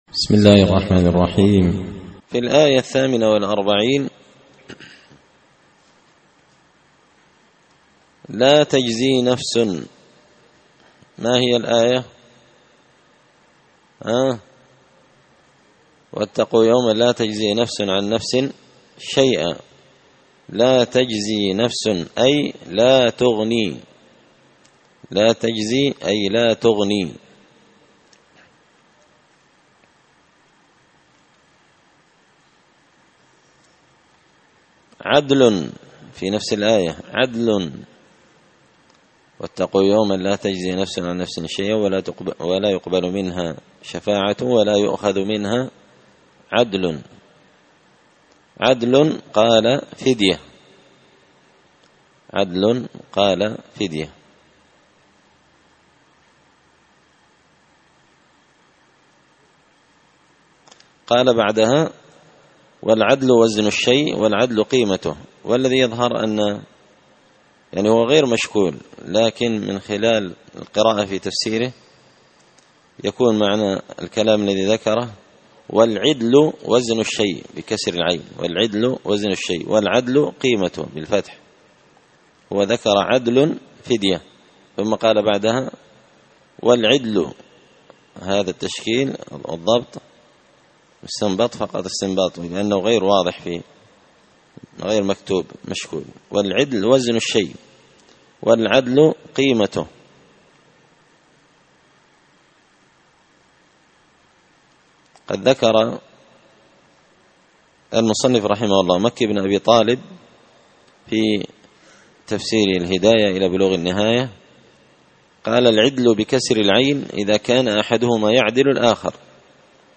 تفسير مشكل غريب القرآن ـ الدرس 11
دار الحديث بمسجد الفرقان ـ قشن ـ المهرة ـ اليمن